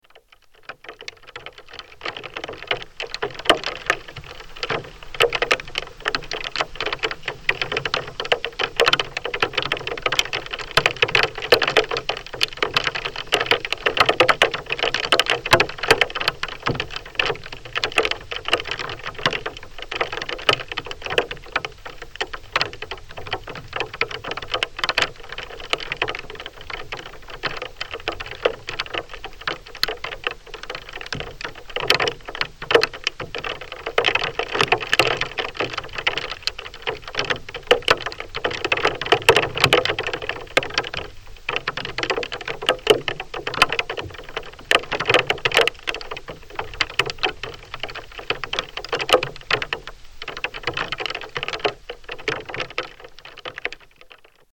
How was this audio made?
Walk in the tiny village of Mooste